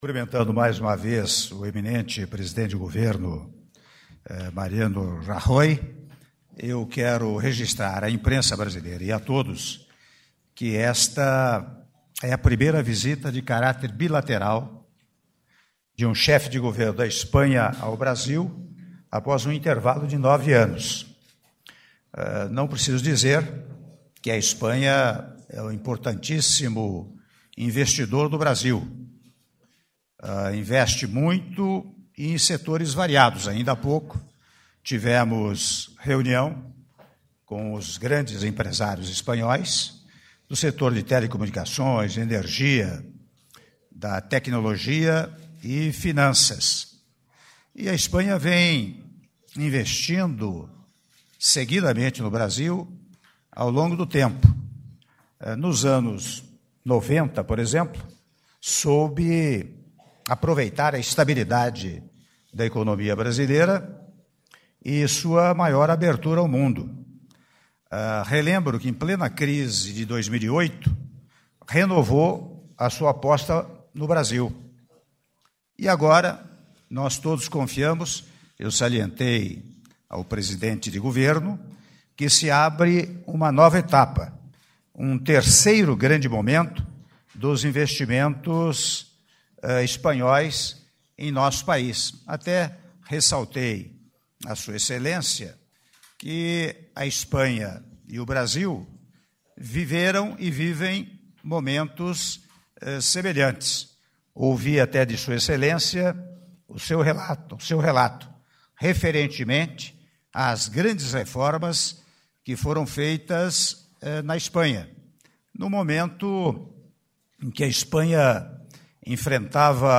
Áudio da Declaração à Imprensa do Presidente da República, Michel Temer, após Assinatura de Atos com o Presidente do Governo da Espanha, Mariano Rajoy - (05min23s) - Brasília/DF